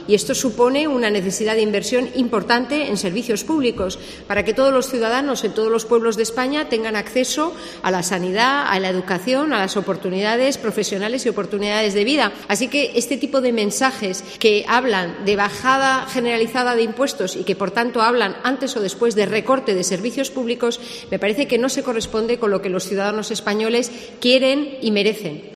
La vicepresidenta, Nadia Calviño, en rueda de prensa en Mérida.